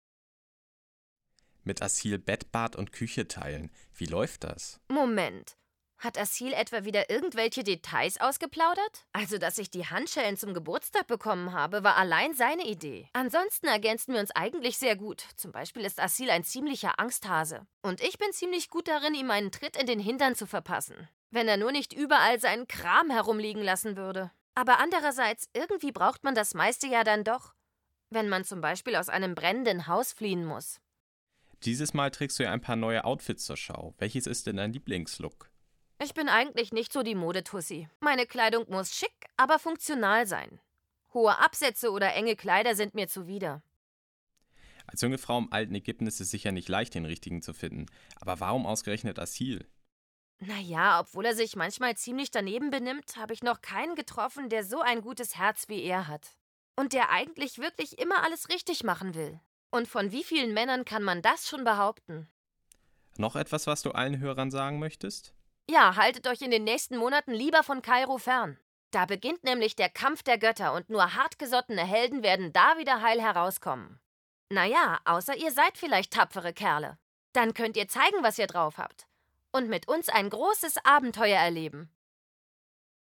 Ankh 3 - Kampf der Götter Interview Thara Datei herunterladen weitere Infos zum Spiel in unserer Spieleliste Beschreibung: Exdklusiv-Interview der Reporter vn Xider und Daedalic mit Thara zu seinem dritten Abenteuer.